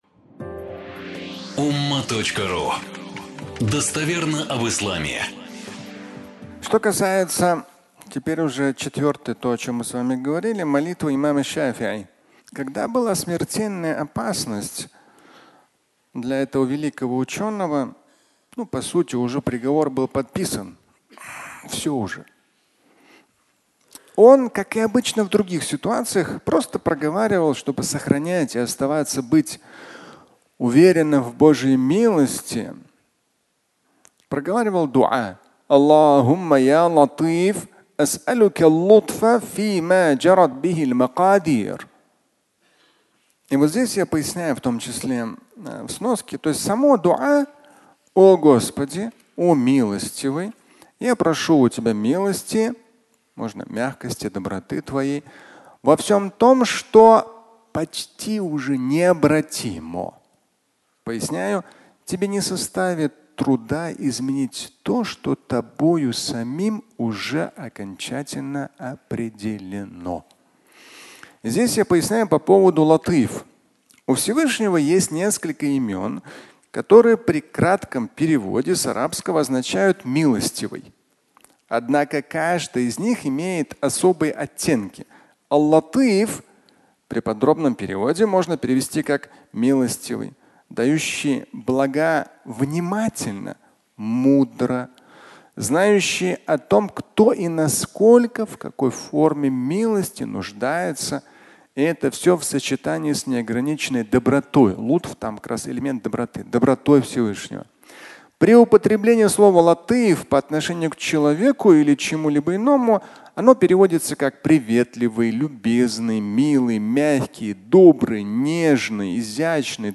Предопределение. Изменить (аудиолекция)